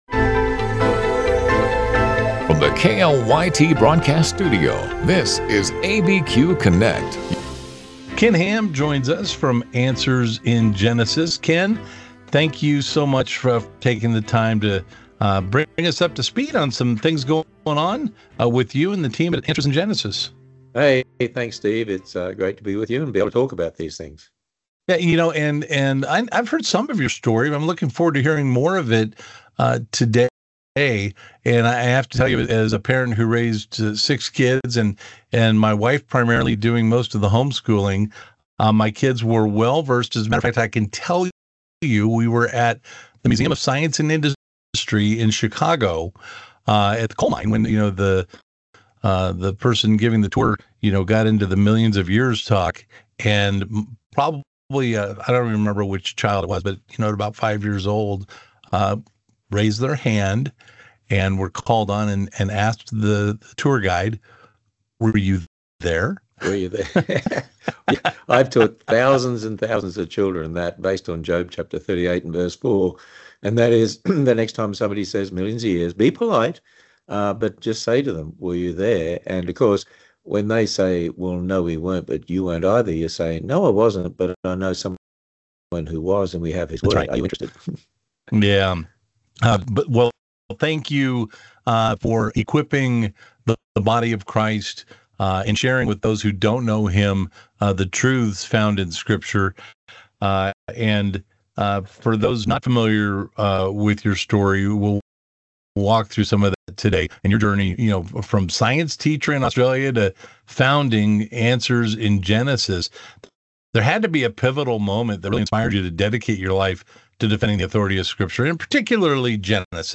Live, local and focused on issues that affect those in the New Mexico area. Tune in for conversations with news makers, authors, and experts on a variety of topics.
Ken Ham with Answers in Genesis joins us, to discuss his foundations and how we was committed to God’s calling from a young age as well as his new book “Miraculous” – which peels back the layers of Answers in Genesis as a global mission, as well as the Creation Museum and Ark Encounter.